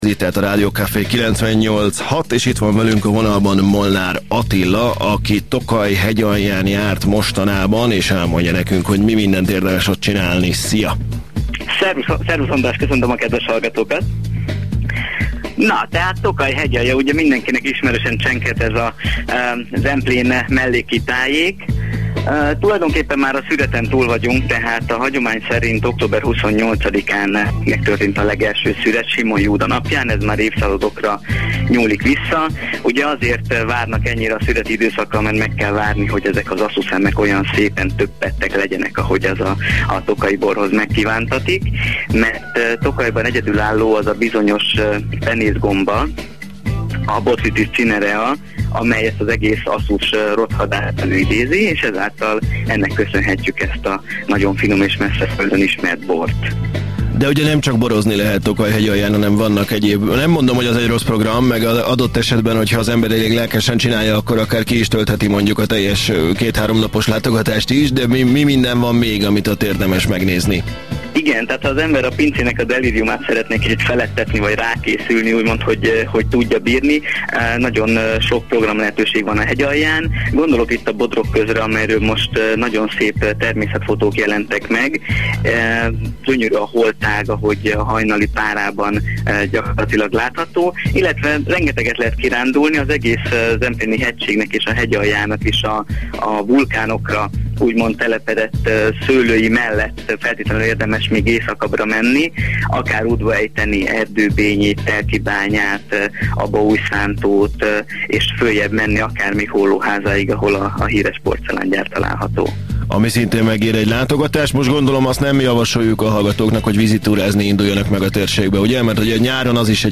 ÉLŐ riportok a Radio Café Műsorában 2007. december 1.